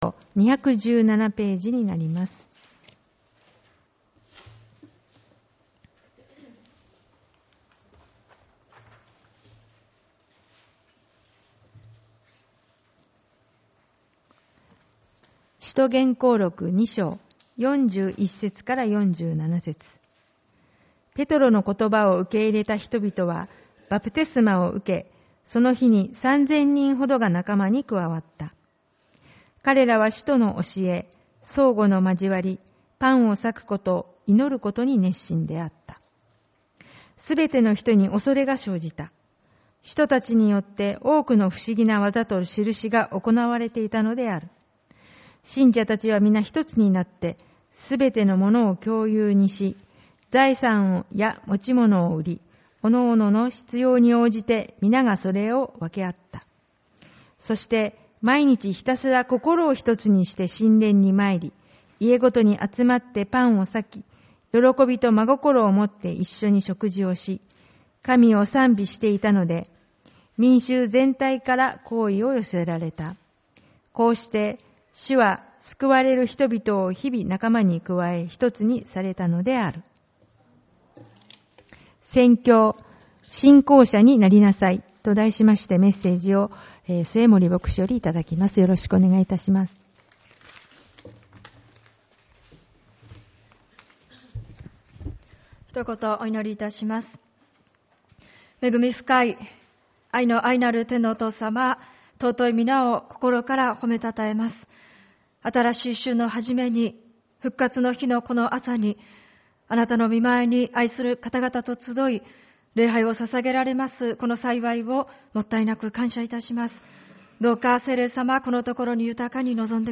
主日礼拝 「信仰者になりなさい」